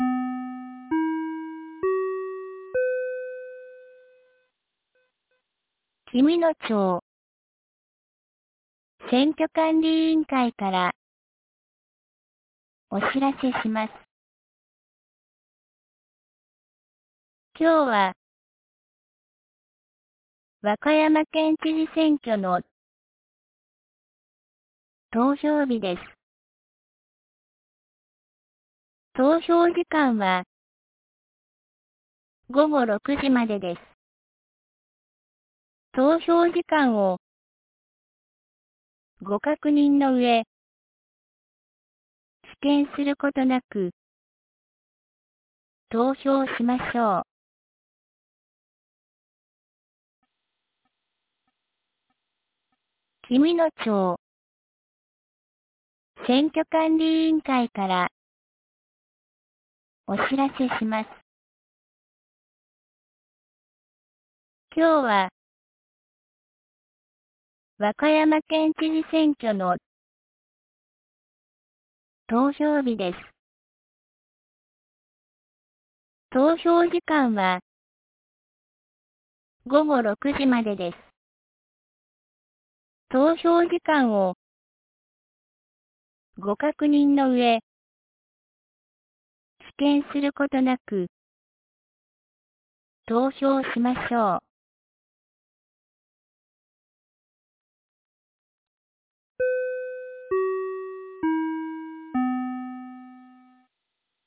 2025年06月01日 17時11分に、紀美野町より全地区へ放送がありました。